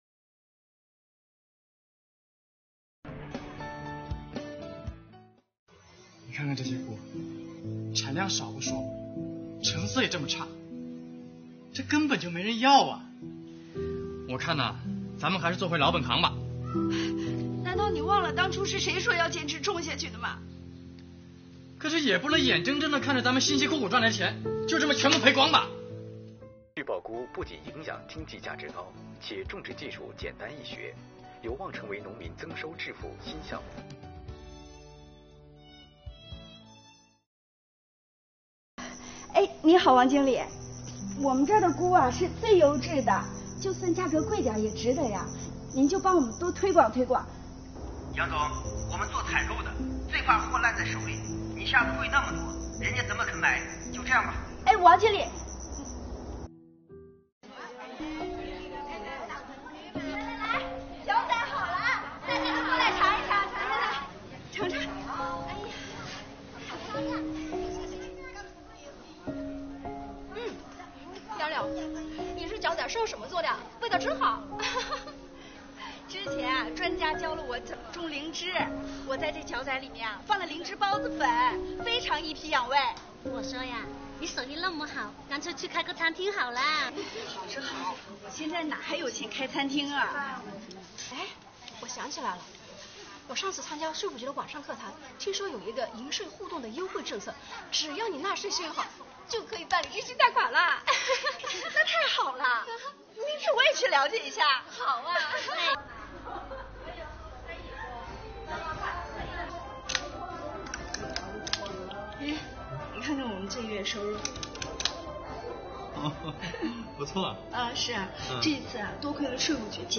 音效方面考究，农田画面的背景音里有蝉鸣声，营造了很好的氛围感，足见创作团队的细腻和用心。